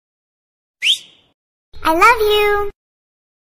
Soft & Romantic Love Message Tone